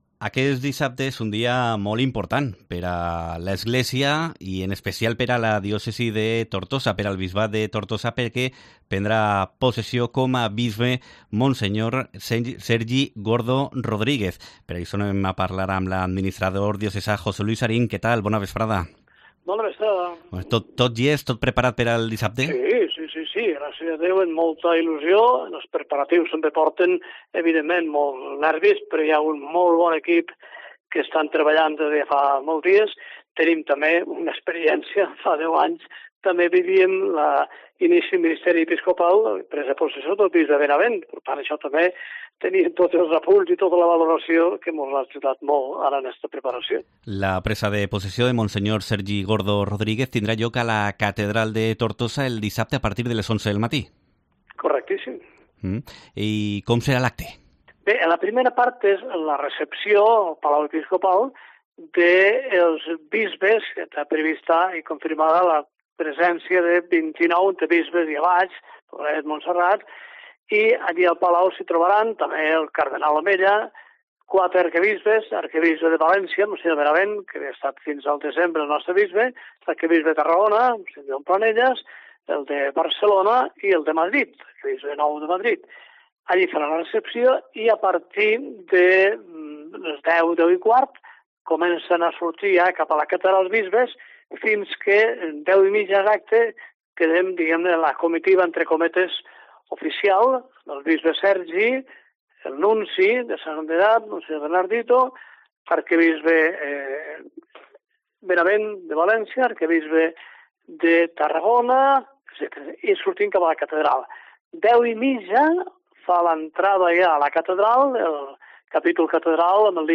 Vinaròs Entrevista Tortosa es prepara per a la presa de possessió com a bisbe de Mons.